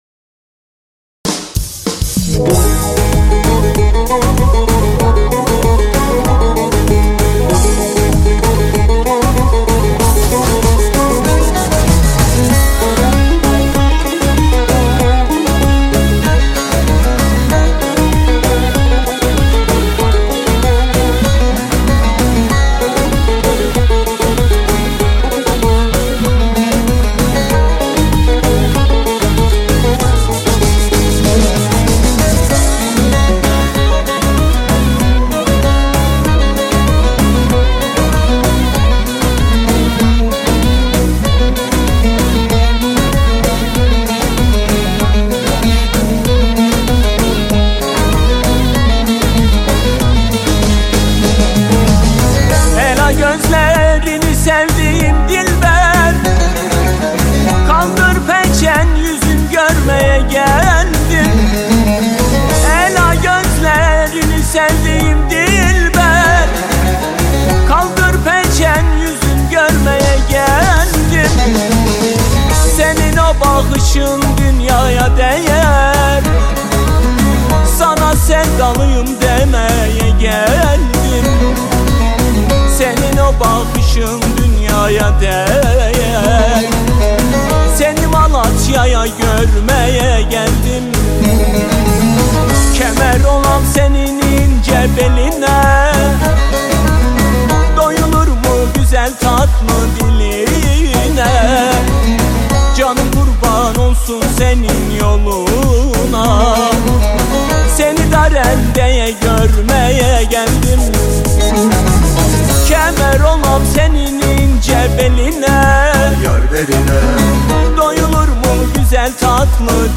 • Категория: Турецкие песни